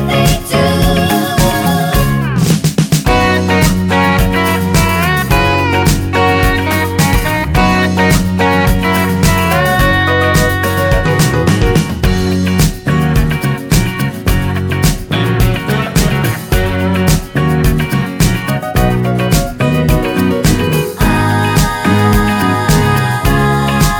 no Backing Vocals Irish 3:13 Buy £1.50